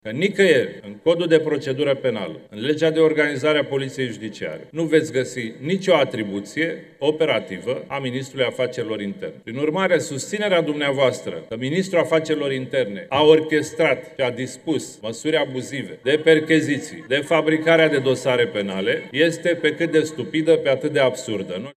Ministrul Afacerilor Interne, Cătălin Predoiu, a răspuns acuzațiilor în fața deputaților: